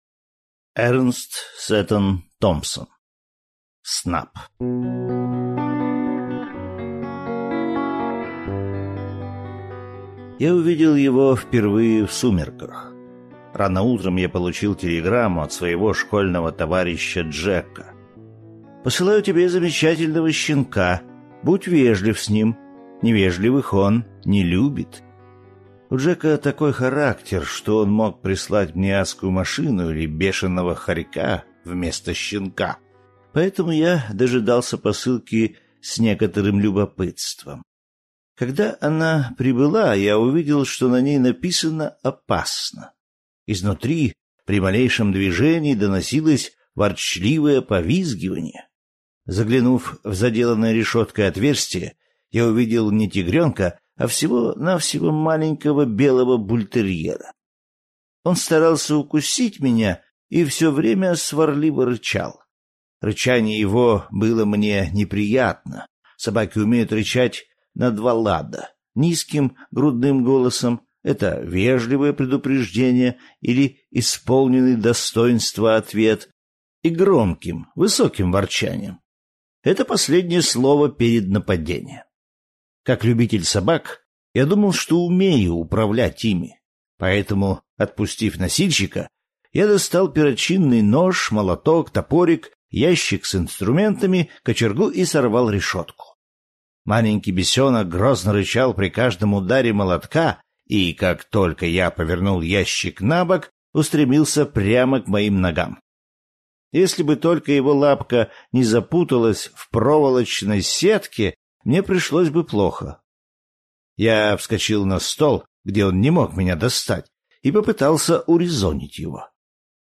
Снап - аудио рассказ Эрнеста Сетона-Томпсона - слушать онлайн